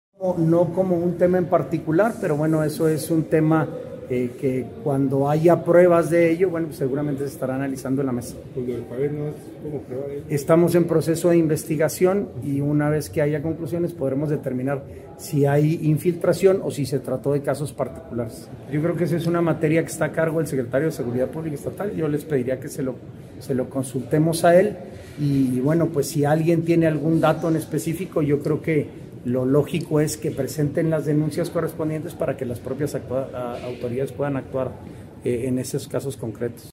AUDIO: SANTIAGO DE LA PEÑA, SECRETARIO GENERAL DE GOBIERNO